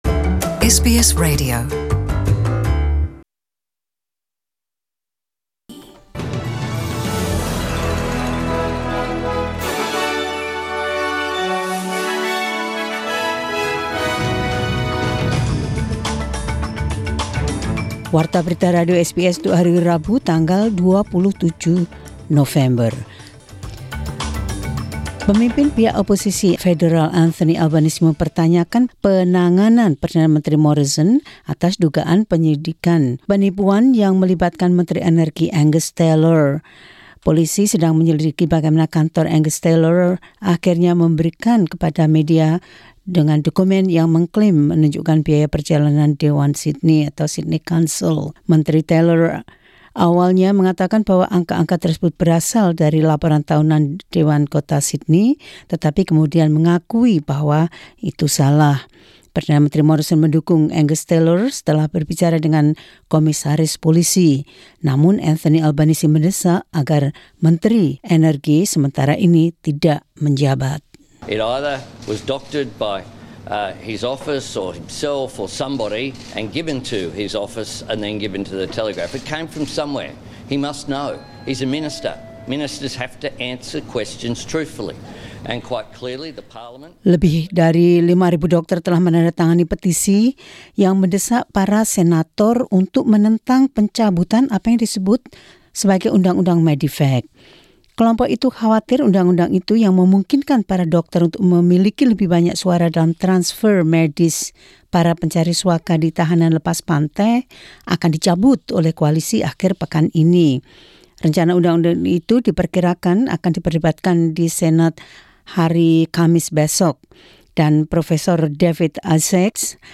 SBS Radio News in Indonesian 27 Nov 2019.